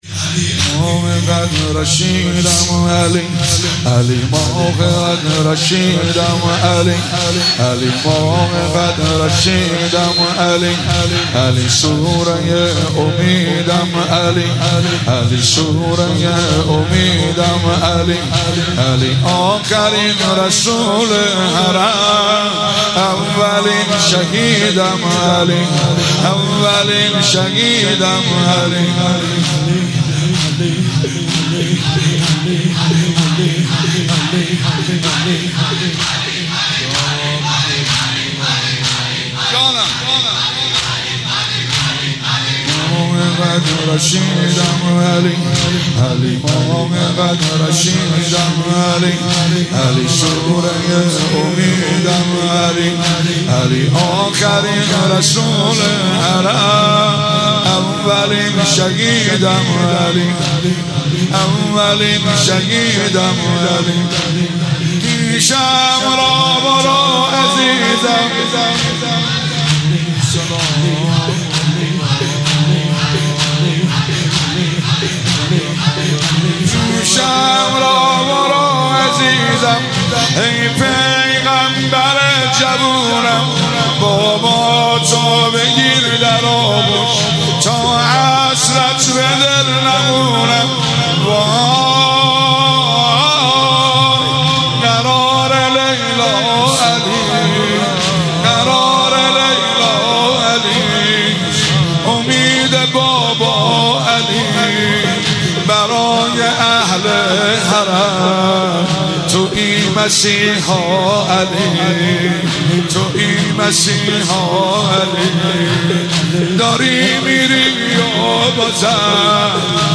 مداح
مناسبت : دهه دوم محرم
قالب : زمینه